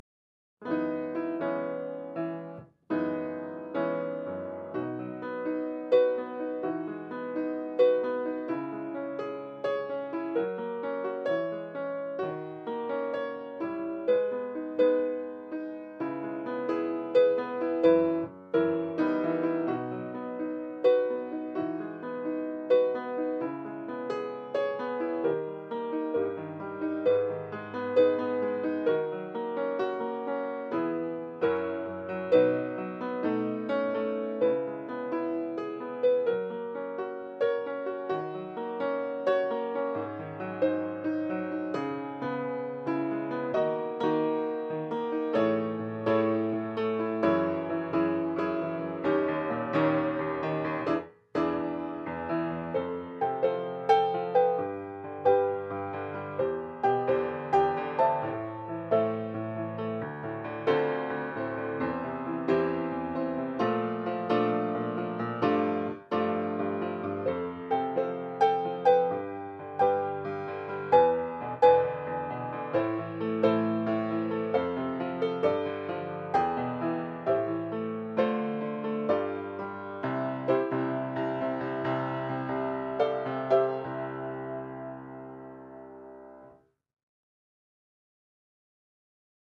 先生役 コラボ者様／生徒役 コラボ者様